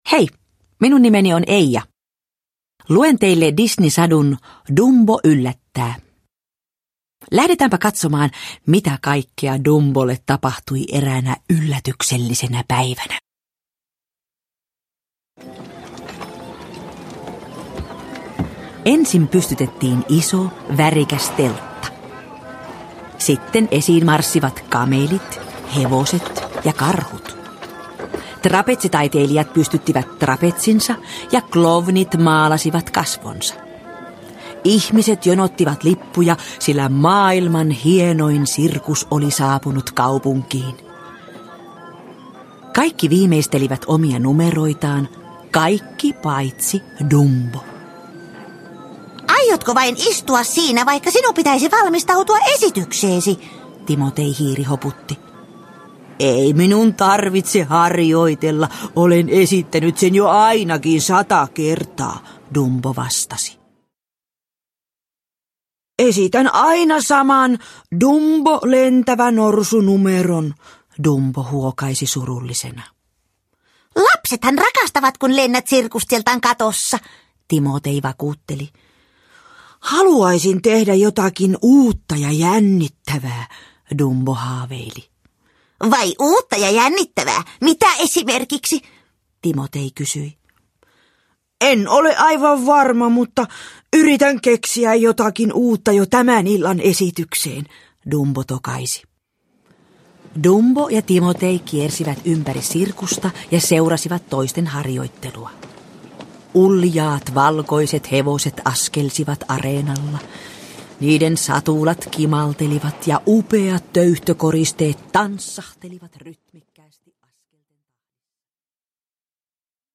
Dumbo yllättää – Ljudbok – Laddas ner